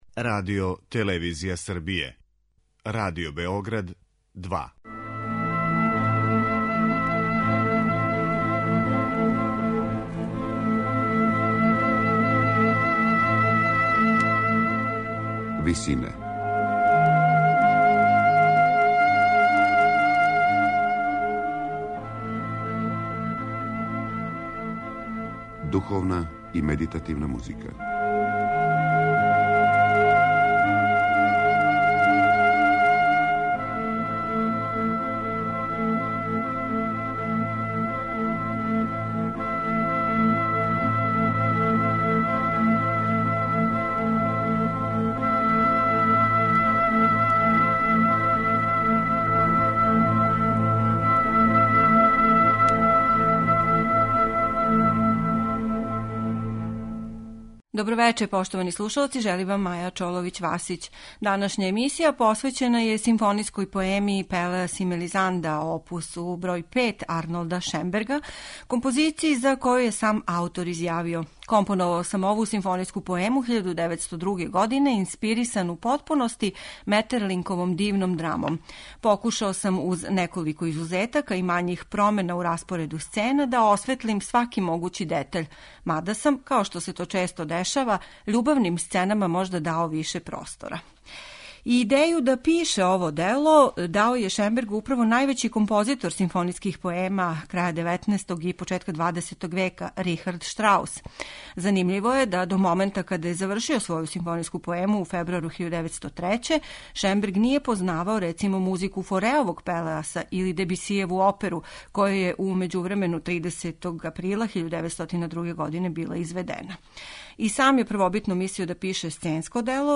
Музички језик ове поеме је у стилу позног романтизма, на ивици експресионизма.